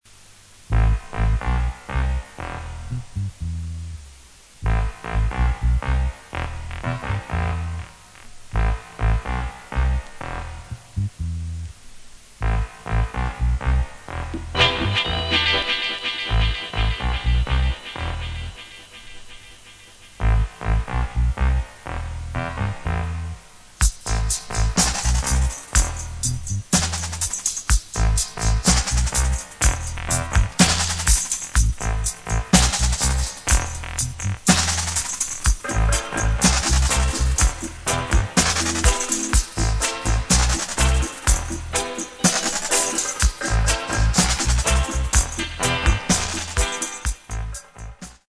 Recorded: Ariwa Studio